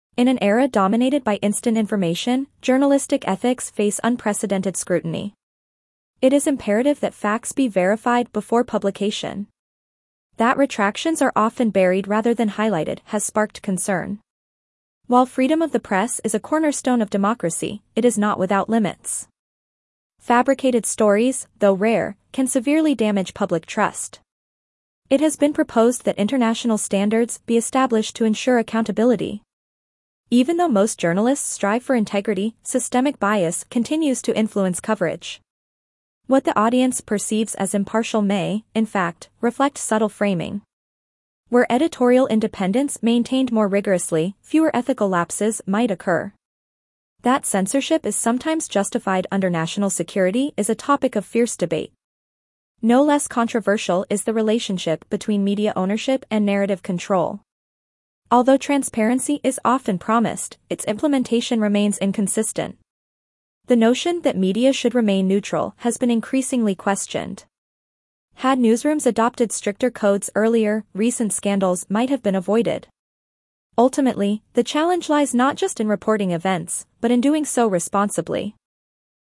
C2 Dictation - Ethics in Global Journalism